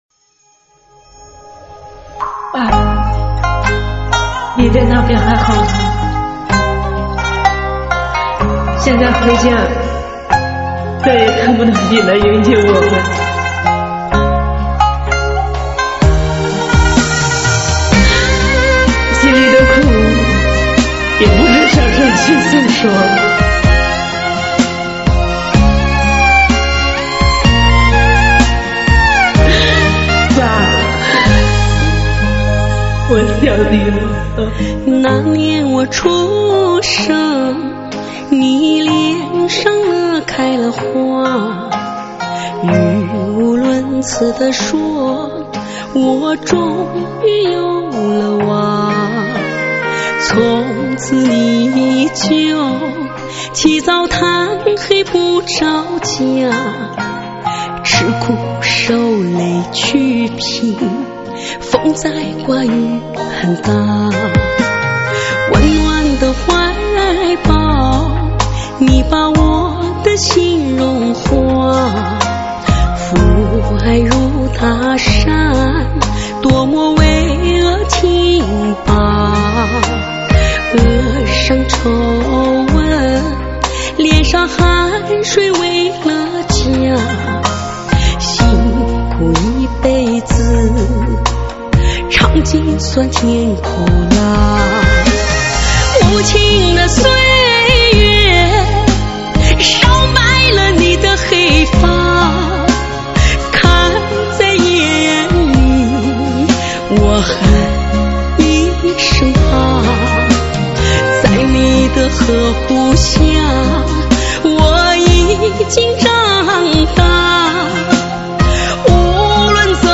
伤感情歌